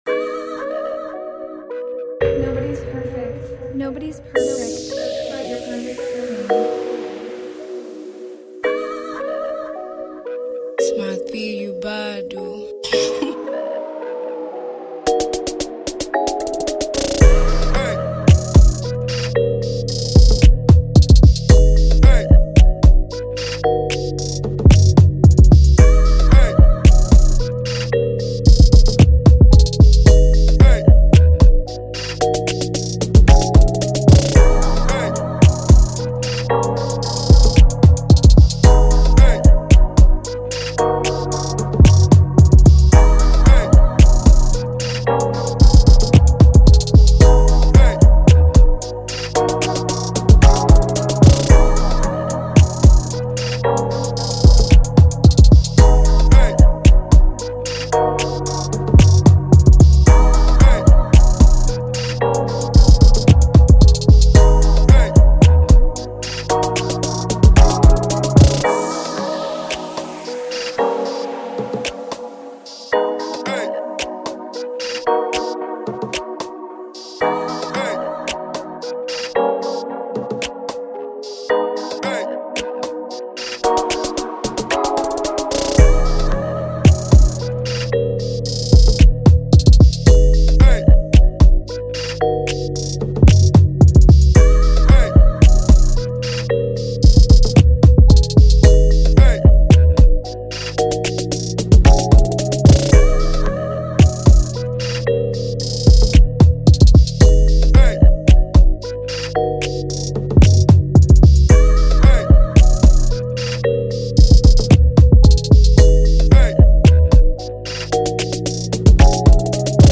FREE BEATS